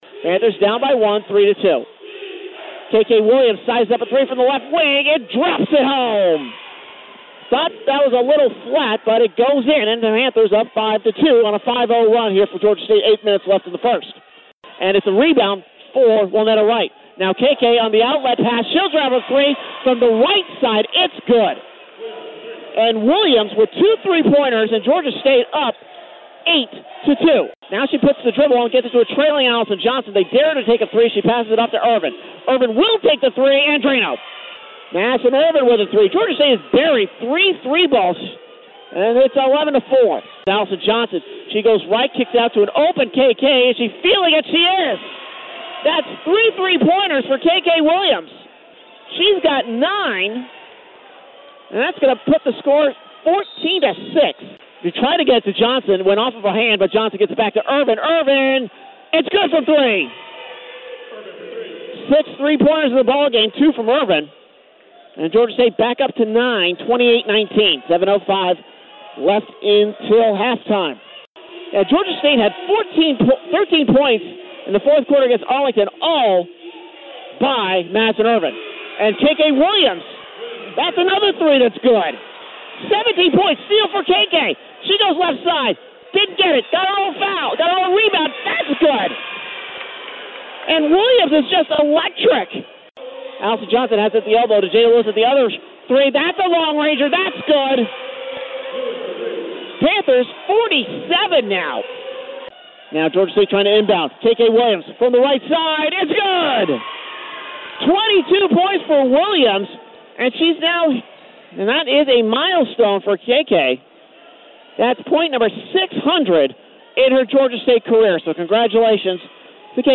A collection of my play-by-play clips.
In their matchup on February 2, Georgia State dropped 12 three-pointers en route to an 83-66 victory over Georgia Southern. Hear my call of most of them.